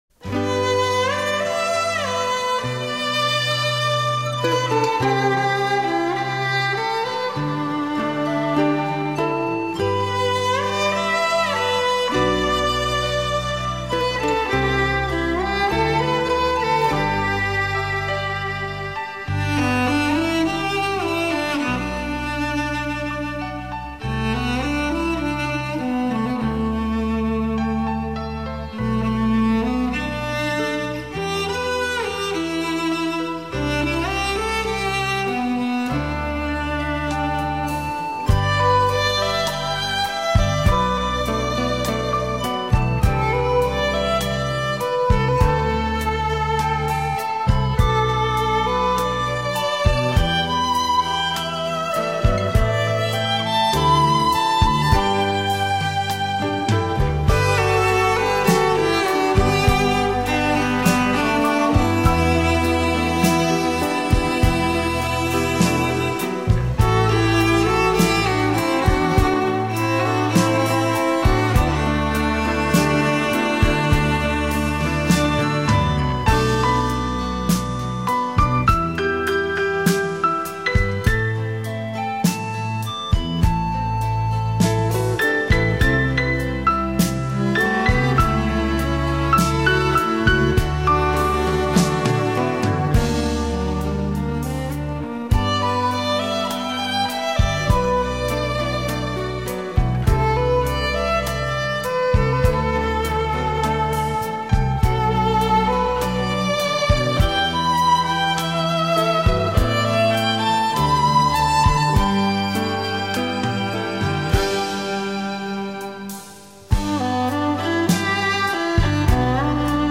琴韵和鸣的乐声